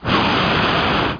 flame2.mp3